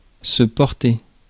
ÄäntäminenFrance:
• IPA: /pɔʁ.te/